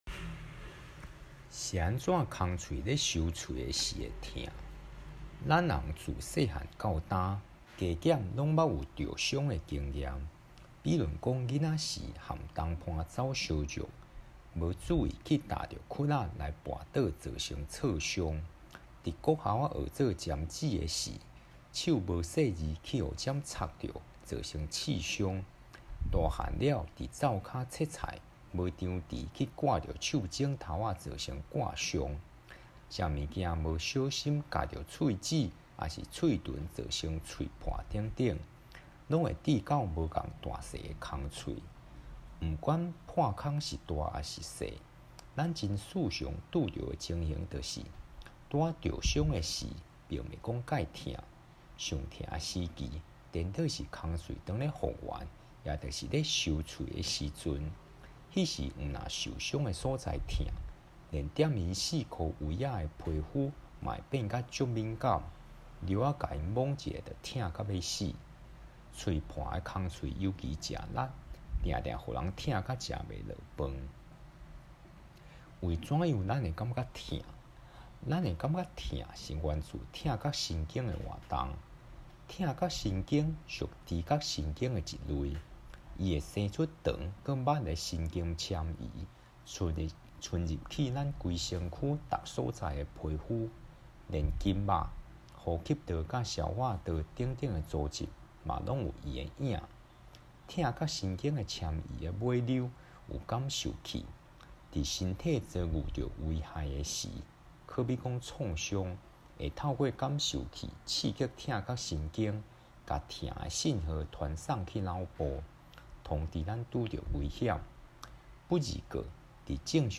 全文朗讀 Tsuân-bûn lóng-tho̍k